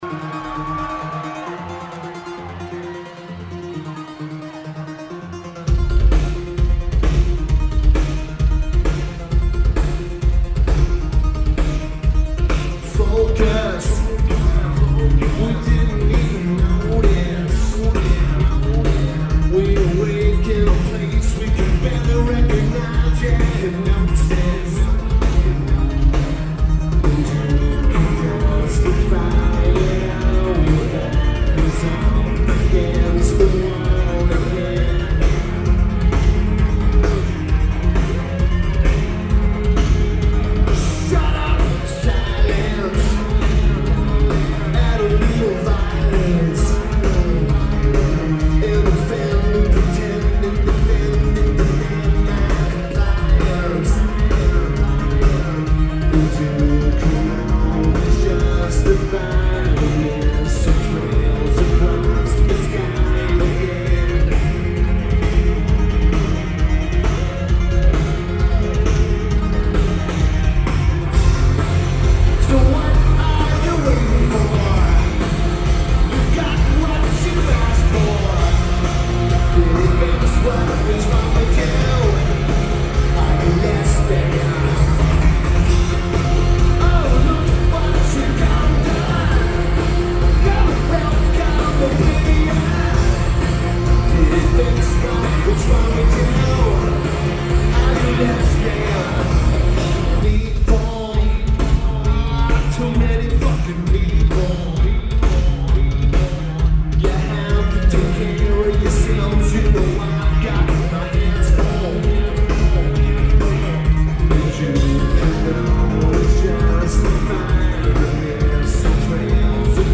Lineage: Audio - AUD (CA14 Card + SP-SPSB-8-MKII + Zoom H1 )
This was recorded near the soundboard.